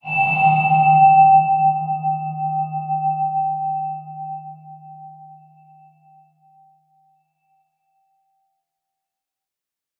X_BasicBells-D#1-ff.wav